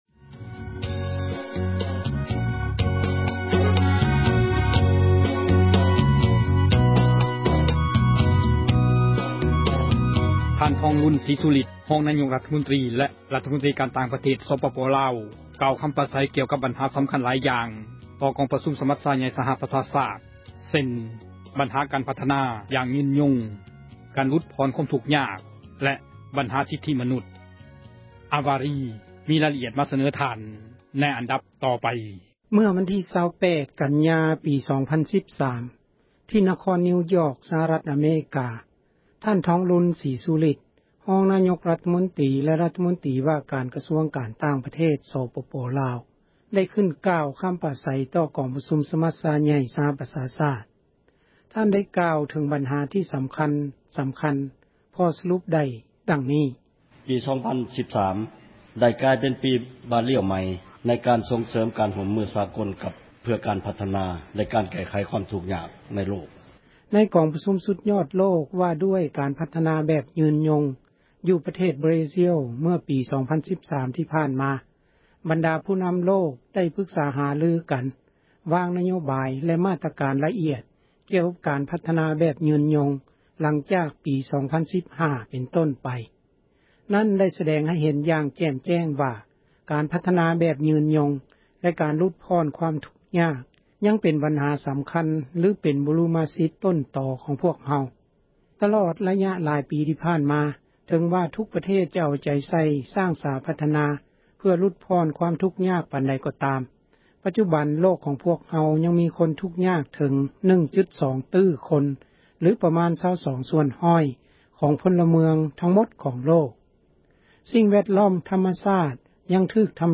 ທ່ານ ທອງລຸນ ສີສຸລິດ ຮອງນາຍົກຣັຖມົນຕຣີ ແລະຣັຖມົນຕຣີການ ຕ່າງປະເທດ ສປປລາວ ກ່າວຄຳປາສັຍ ກ່ຽວກັບ ບັນຫາ ສຳຄັນ ຫລາຍຢ່າງ ຕໍ່ ກອງປະຊຸມ ສມັດຊາໃຫ່ຍ ສະຫະປະຊາຊາດ ເຊັ່ນ ບັນຫາ ການພັທນາ ແບບຍືນຍົງ ການຫລຸດຜ່ອນ ຄວາມທຸກຍາກ ແລະ ບັນຫາ ສິດທິມະນຸດ.